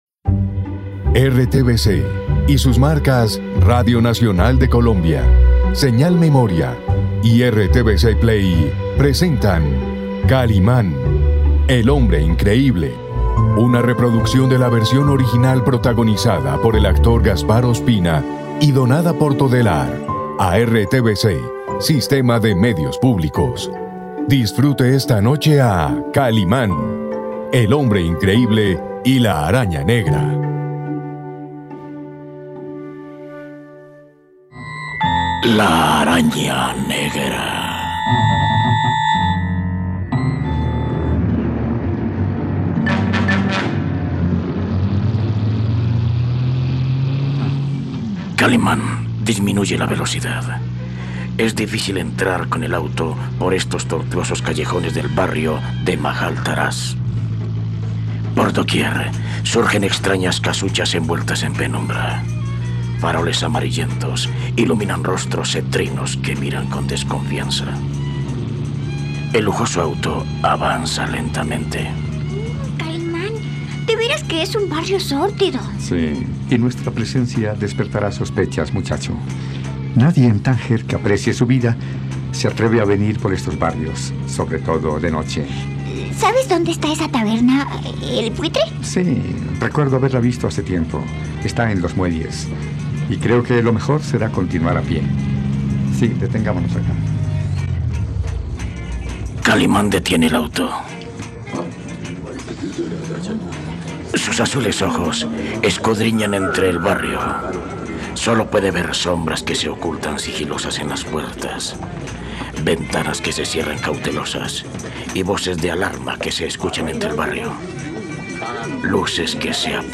No te pierdas esta radionovela completa en RTVCPlay.